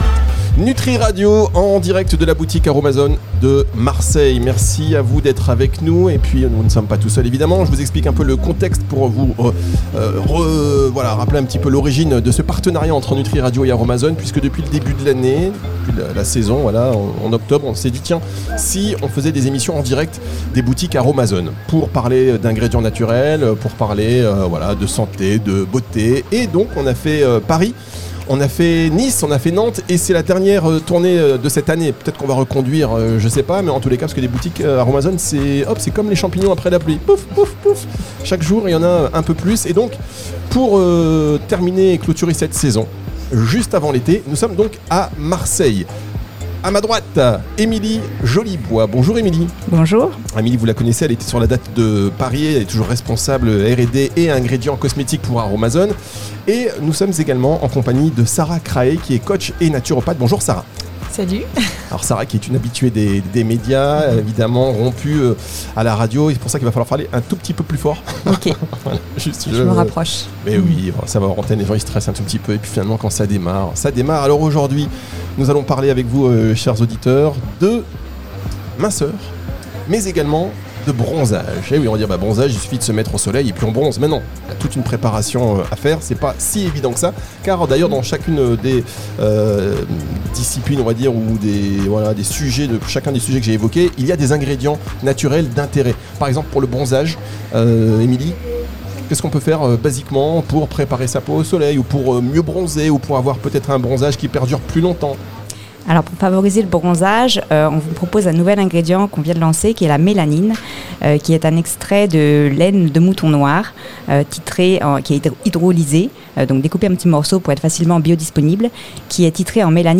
EN DIRECT DE LA BOUTIQUE AROMA ZONE DE MARSEILLE.